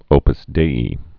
(ōpəs dāē, dā)